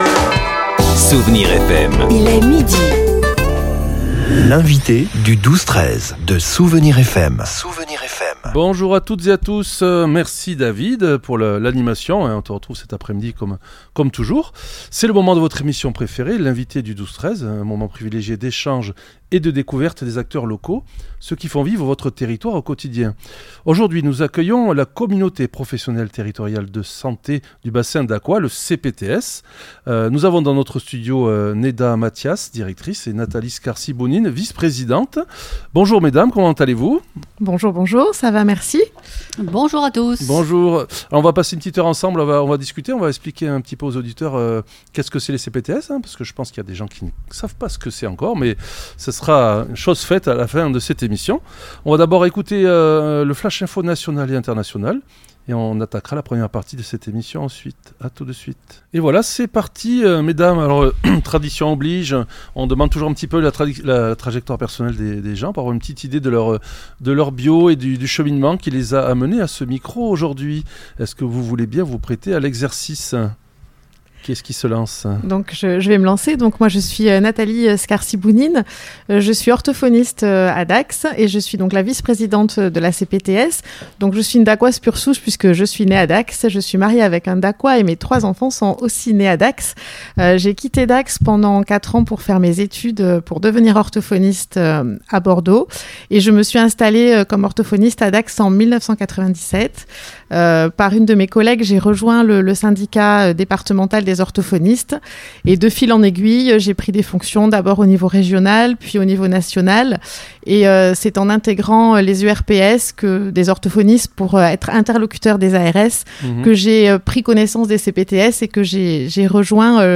Pendant une heure d’échange, nos invitées ont présenté le rôle essentiel de la CPTS : mieux organiser l’offre de soins sur le territoire, faciliter l’accès aux professionnels de santé et améliorer la prise en charge des habitants du Bassin Dacquois.